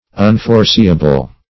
Unforeseeable \Un`fore*see"a*ble\, a.